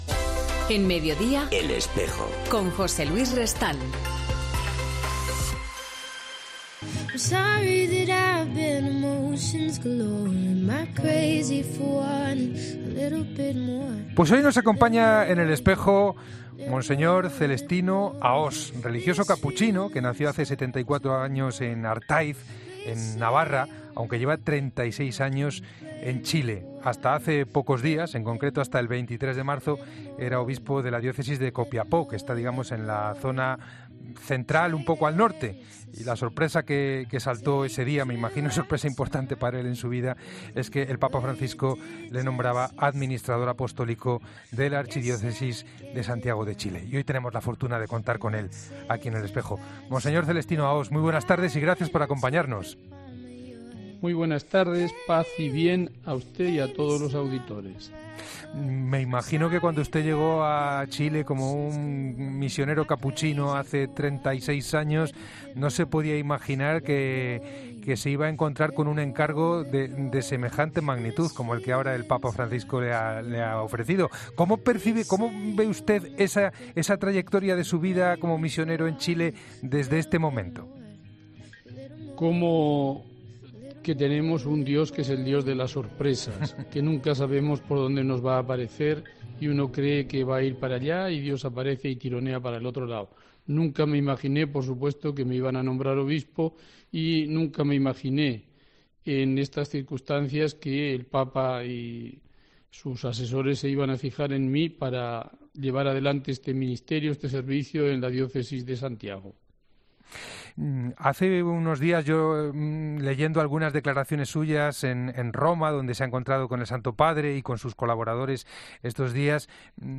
Ha hablado para El Espejo de Cope, desde el Vaticano, donde se ha reunido con el Papa Francisco.